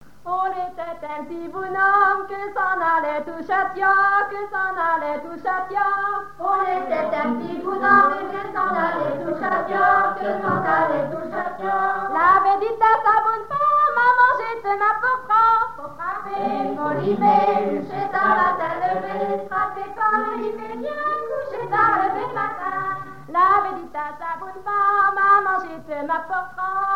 danse : ronde : demi-rond
chansons à danser ronds et demi-ronds
Pièce musicale inédite